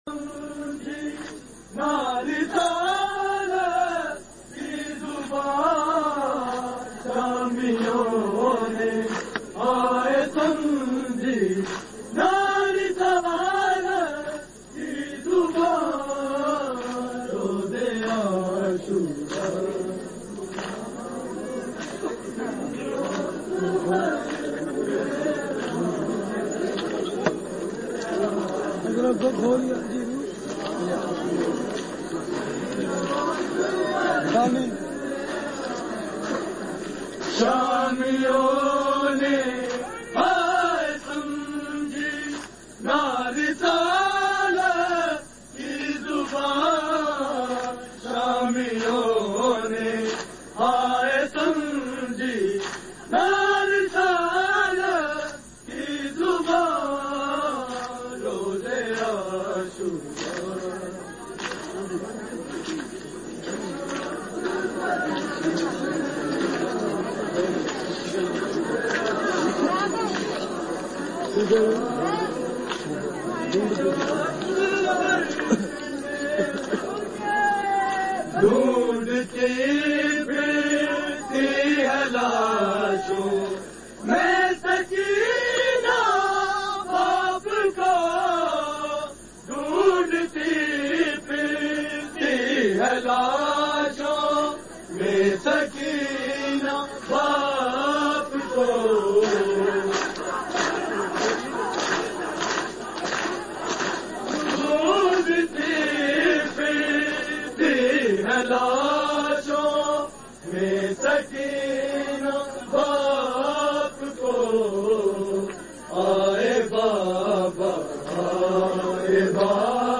Recording Type: Live
Location: Purani Kotwali Lahore